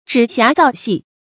指瑕造隙 zhǐ xiá zào xì
指瑕造隙发音
成语注音ㄓㄧˇ ㄒㄧㄚˊ ㄗㄠˋ ㄒㄧˋ